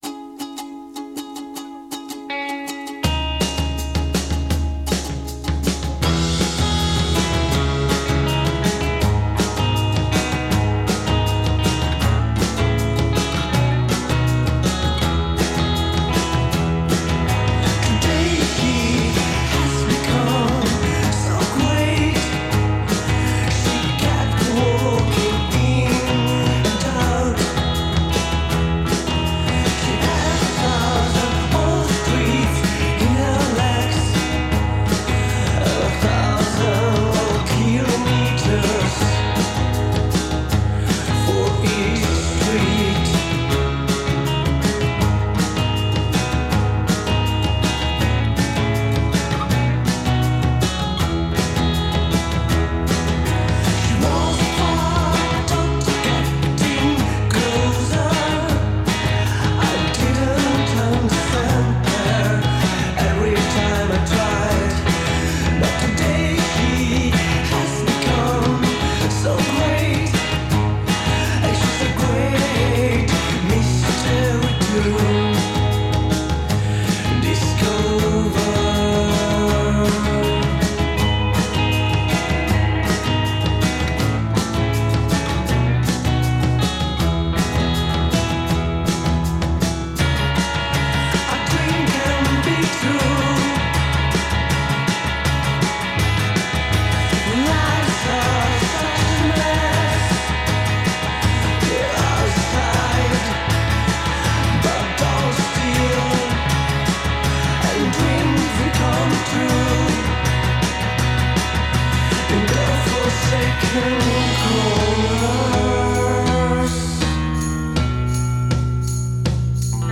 warm and emotional folk-rock